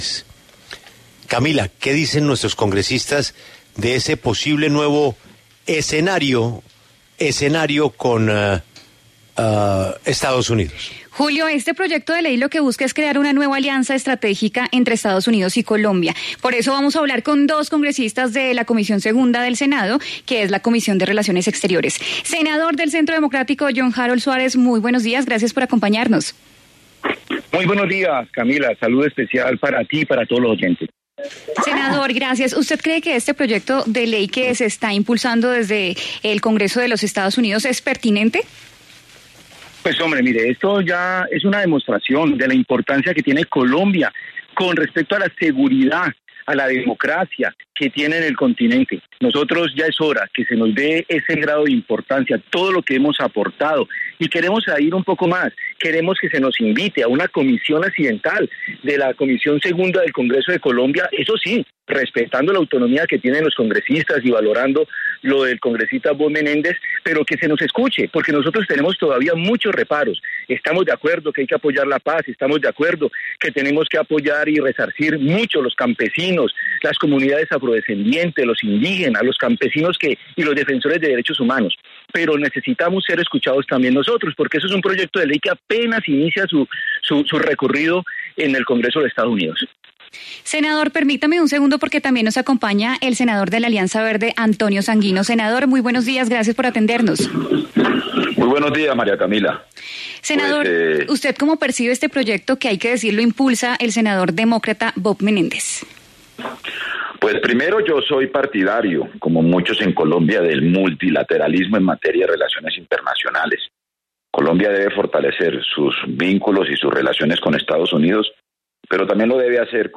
En diálogo con La W, los senadores John Harold Suárez y Antonio Sanguino se refirieron a la iniciativa que presentará el presidente del Comité de Relaciones Exteriores del Senado de Estados Unidos.